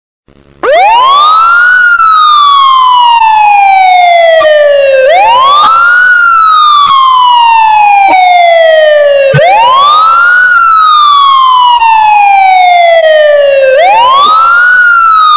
Police Lights and Siren ringtone free download
Sound Effects